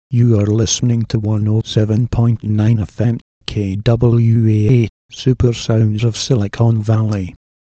from Scotland.